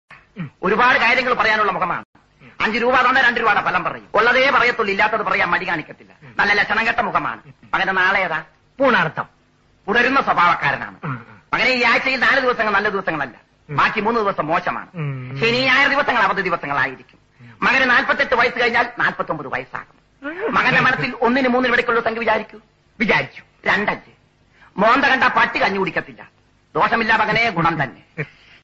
best flute ringtone download | comedy song ringtone